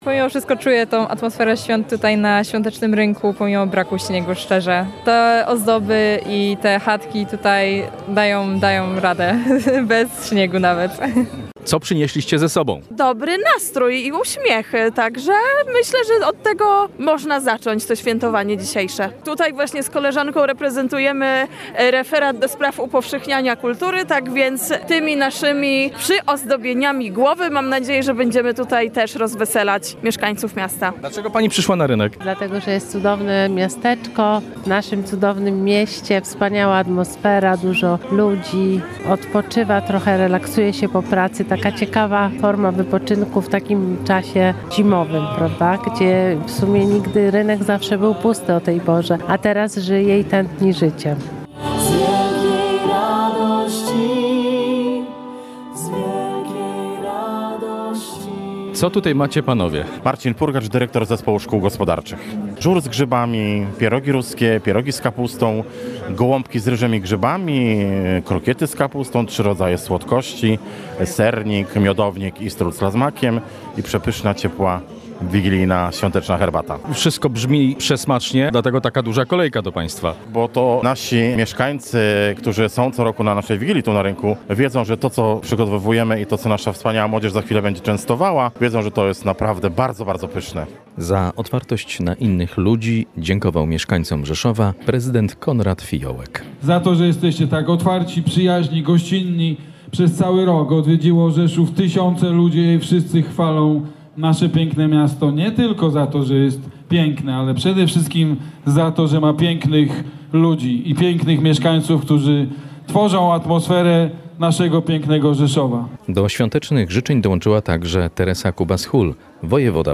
Na rzeszowskim Rynku odbyła się dziś miejska Wigilia z udziałem mieszkańców i zaproszonych gości.
Życzenia rzeszowianom złożył prezydent miasta Konrad Fijołek. Dziękował mieszkańcom za otwartość i gościnność wobec osób przyjeżdżających do Rzeszowa.